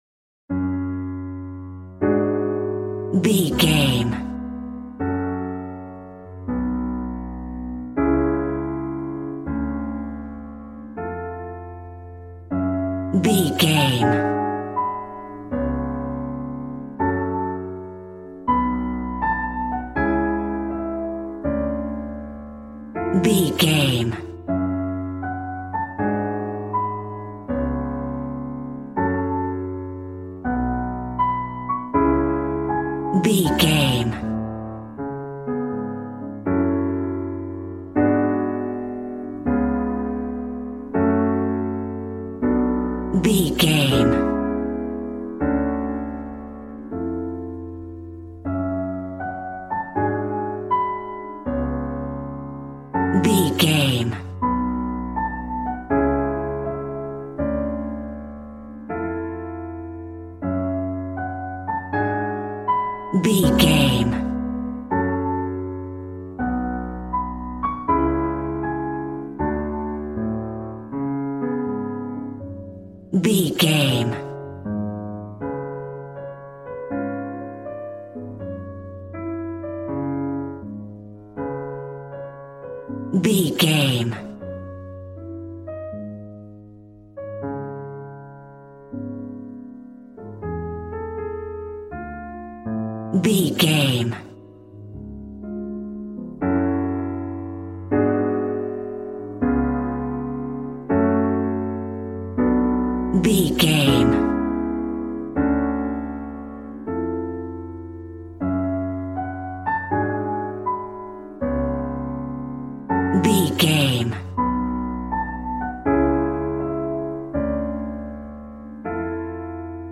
Aeolian/Minor
D♭
smooth
piano
drums